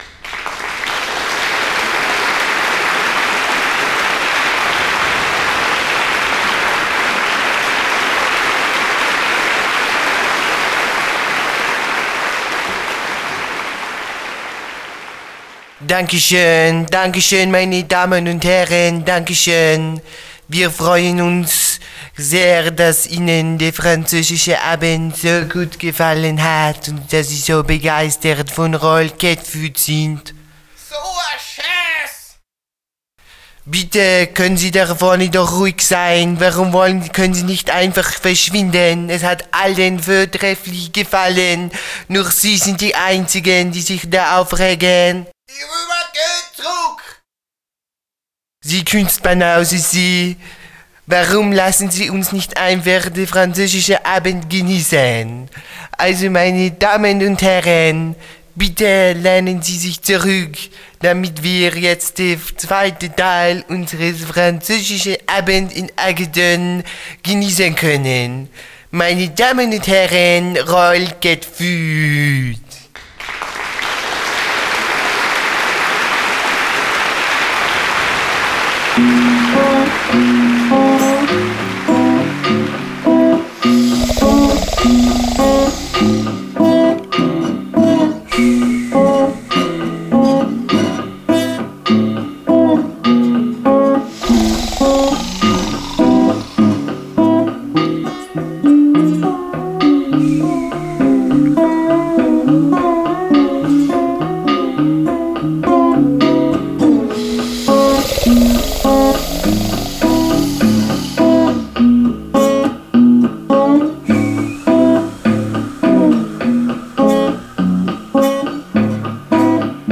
Genre: Freie Musik - Französische Avantgarde